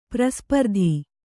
♪ praspardhi